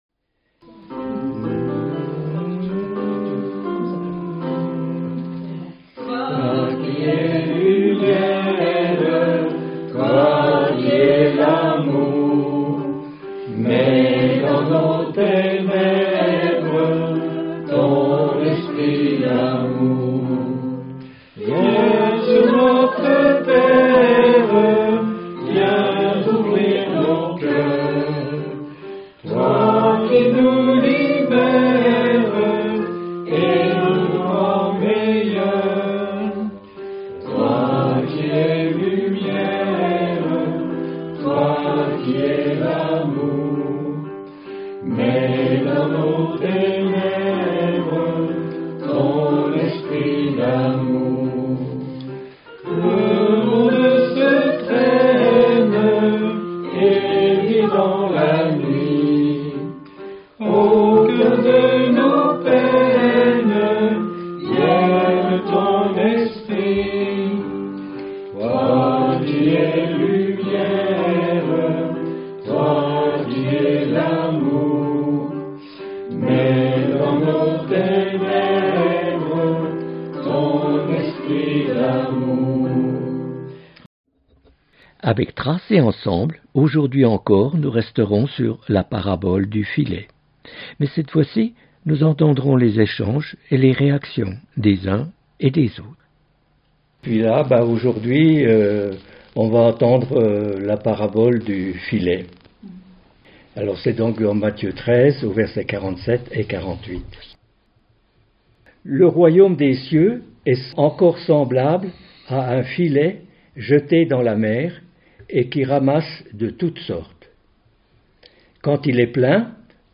Etude biblique